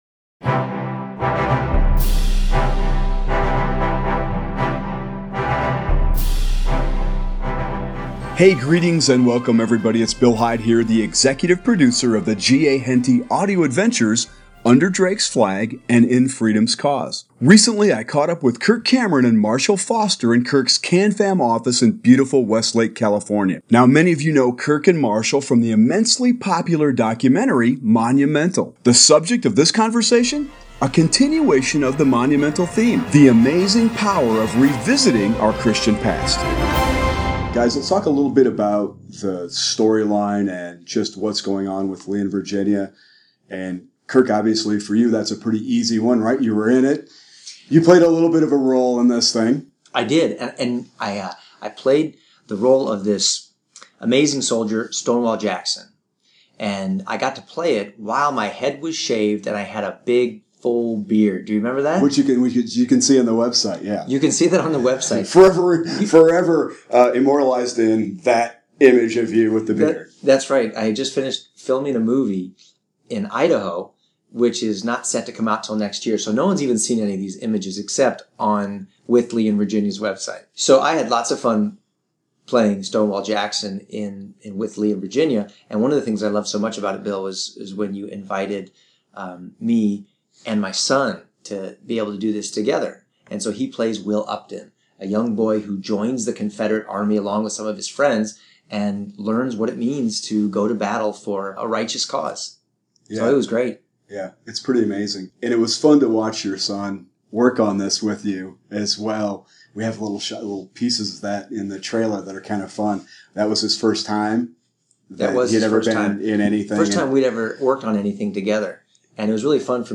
However, there was one problem: We were in Kirk Cameron’s office with no recording studio in sight. So what did I do? I pulled out my iPad and recorded our conversation using the voice recorder app.!